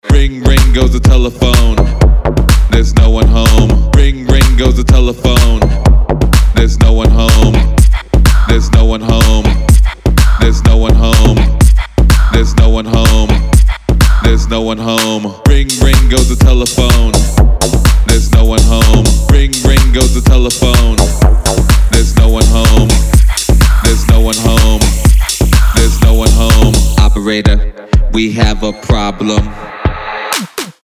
club
house